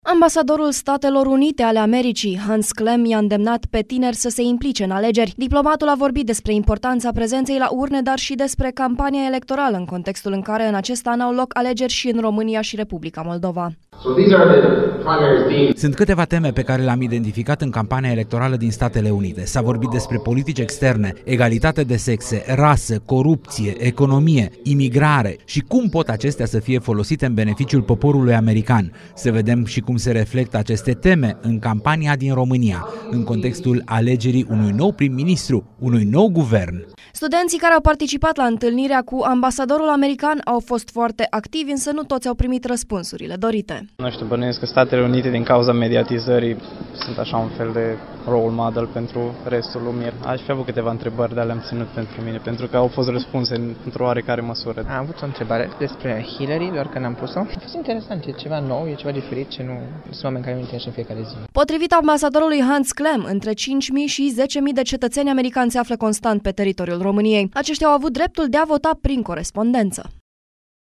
Alegerile americane au reprezentat una dintre temele dezbătute astăzi la Universitatea de Vest din Timişoara. Ambasadorului Statelor Unite ale Americii, Hans Klemm a stat de vorbă cu studenții, a răspuns la întrebări şi a îndemnat tinerii, în contextul alegerilor din decembrie, să iasă la urne.